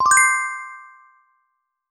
AddScore.mp3